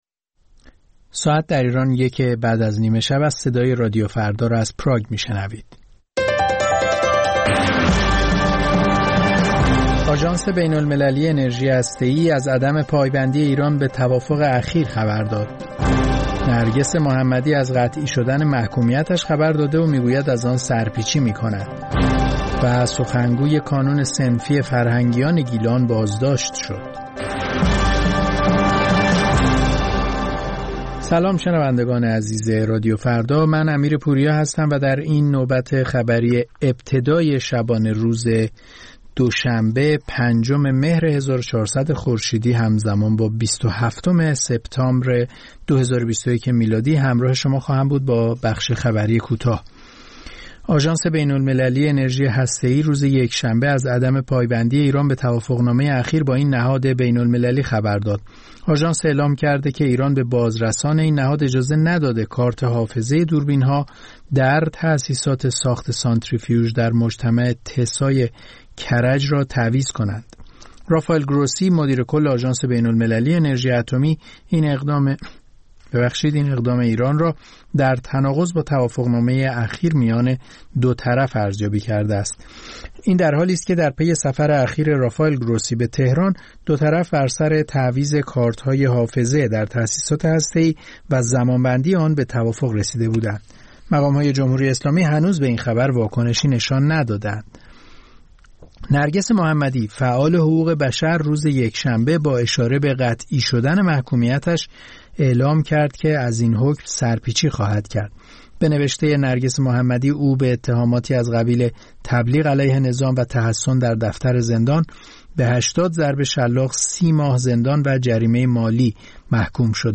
سرخط خبرها ۱:۰۰